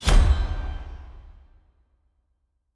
pgs/Assets/Audio/Fantasy Interface Sounds/Special Click 13.wav at master
Special Click 13.wav